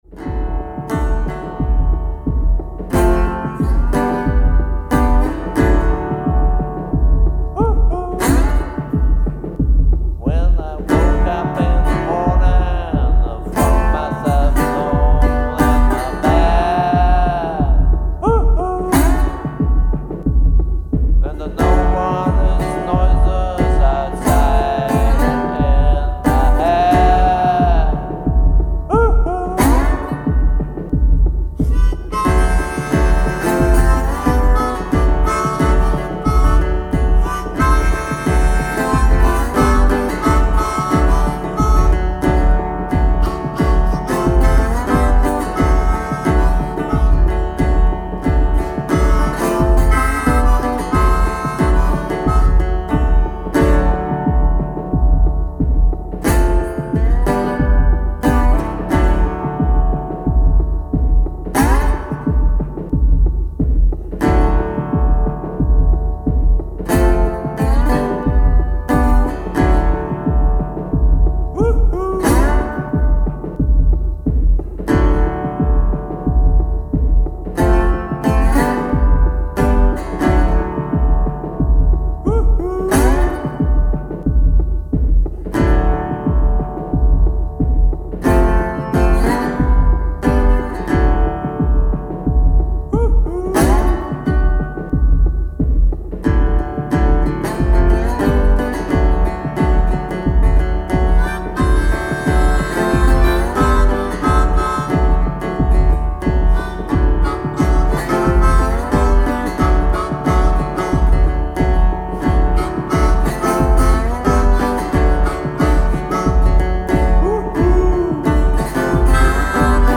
Tempo: 90 bpm / Datum: 05.10.2017